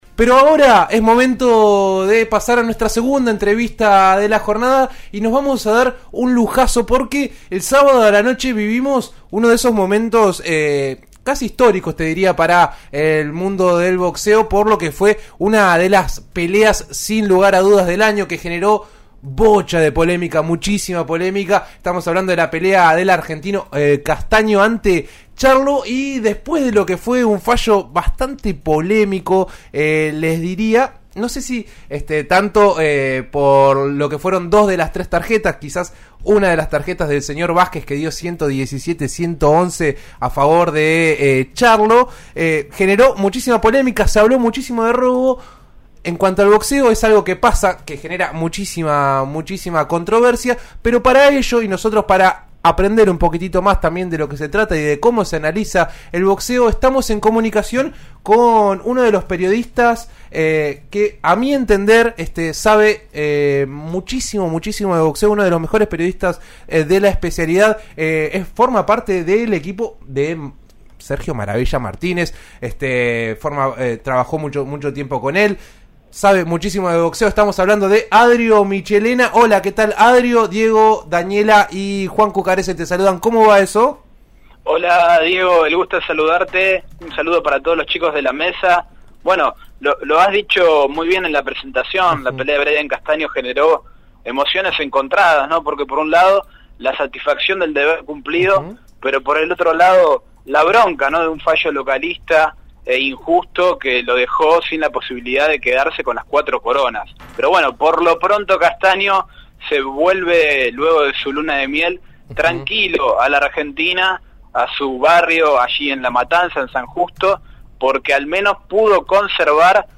este lunes dialogó con «En eso estamos» de RN Radio